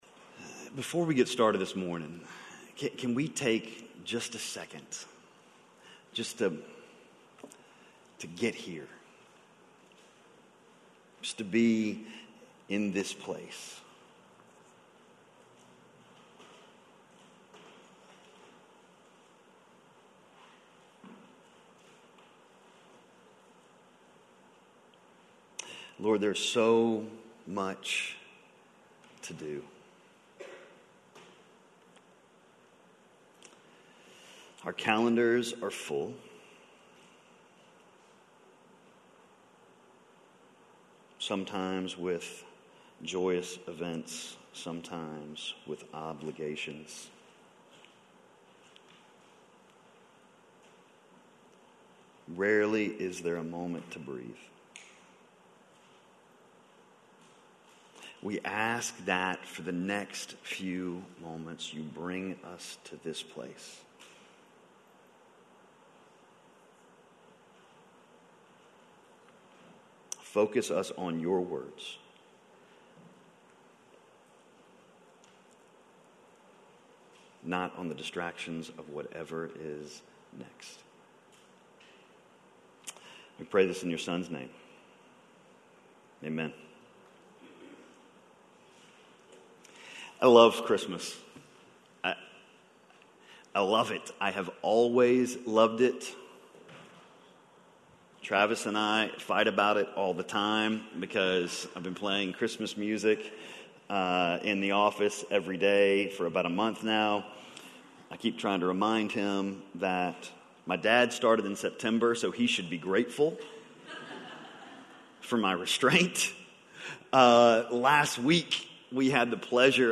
We can know…Stability - Sermon - Lockeland Springs